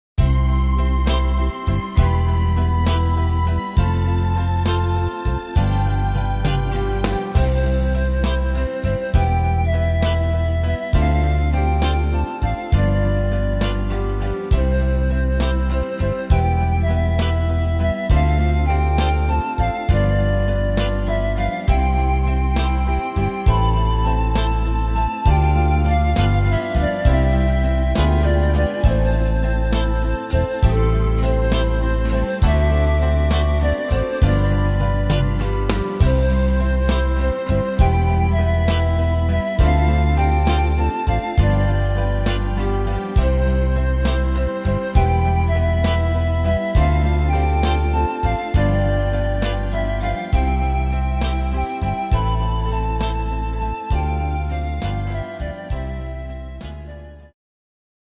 Tempo: 67 / Tonart: C-Dur